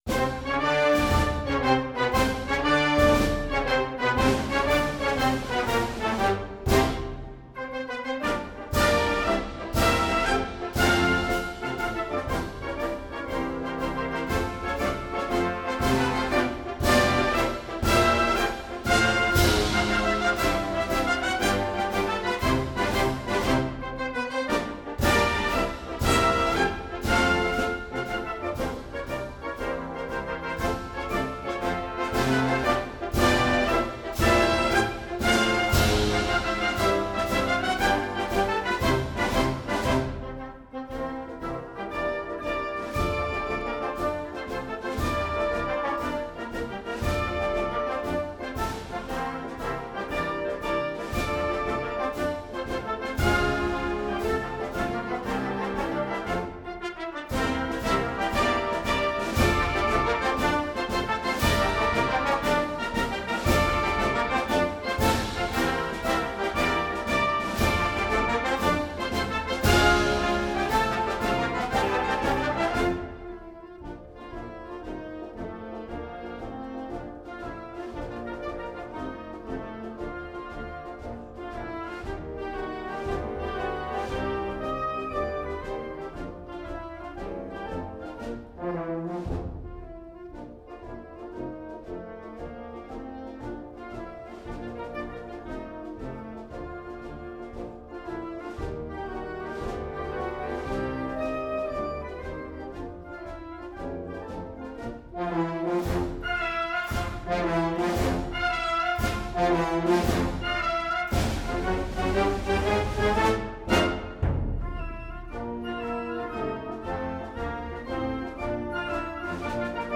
Military songs, cadences, ensembles, bands and more
Popular Marching Favorites